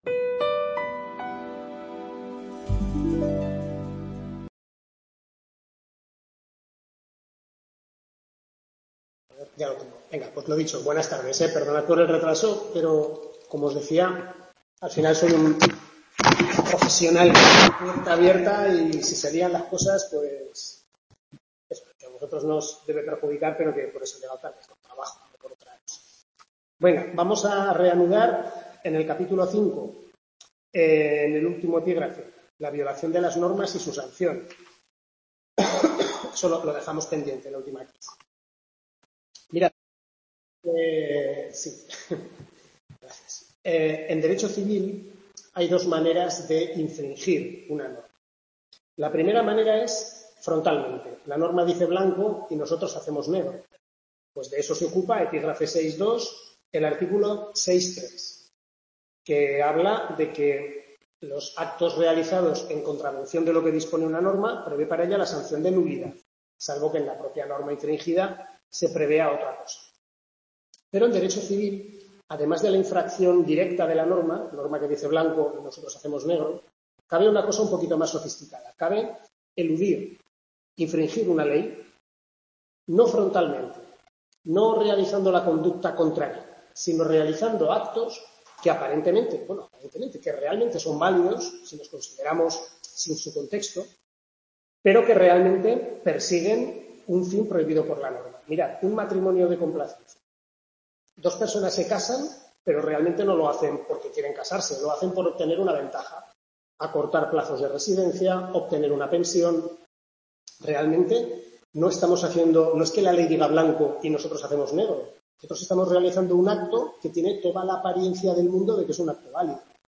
Tutoría 2/6 Civil I (Parte General y Personas)